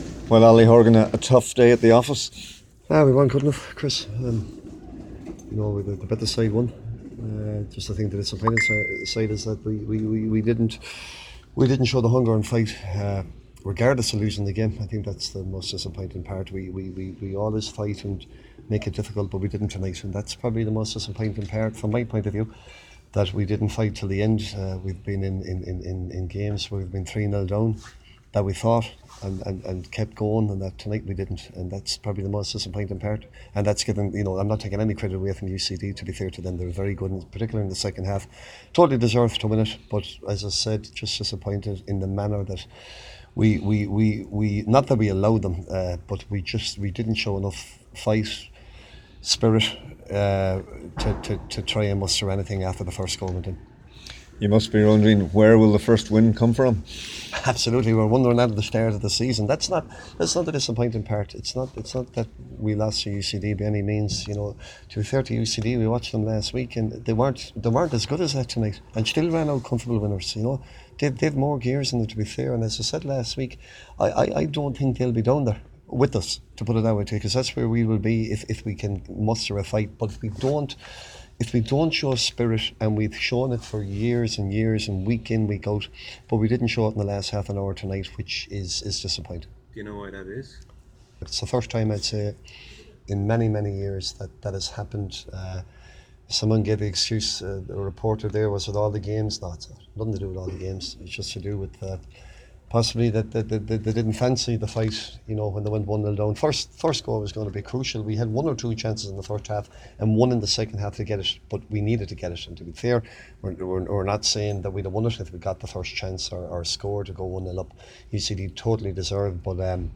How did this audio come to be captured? Speaking after the game